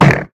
Minecraft Version Minecraft Version snapshot Latest Release | Latest Snapshot snapshot / assets / minecraft / sounds / entity / shulker / hurt2.ogg Compare With Compare With Latest Release | Latest Snapshot
hurt2.ogg